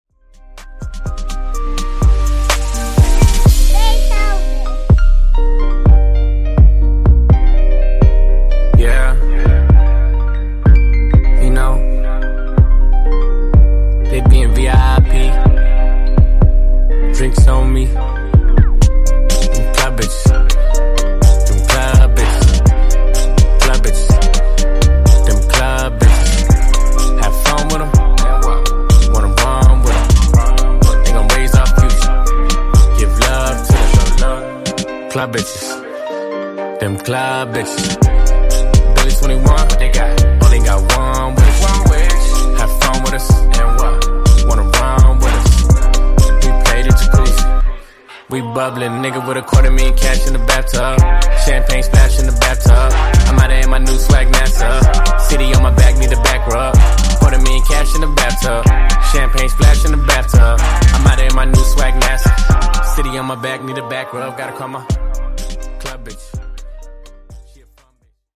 Genres: AFROBEAT , RE-DRUM
Clean BPM: 128 Time